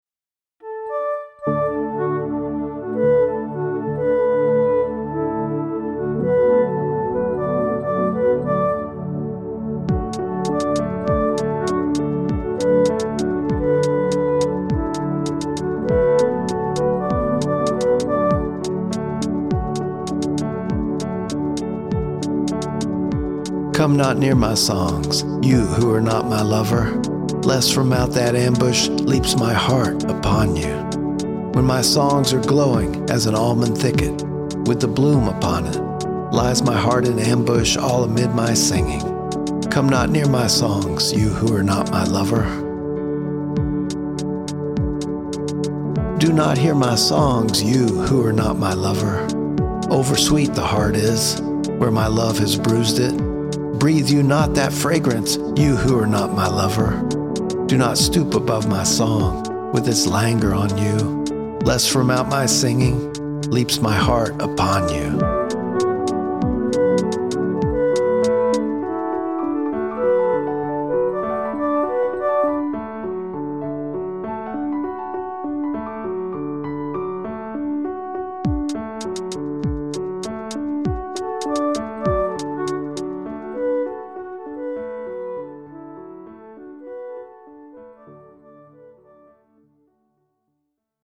Beyond copyright concerns, are there ethical cultural questions raised about Austin’s “reëxpression” of Indian songs (and my subsequent cover version)?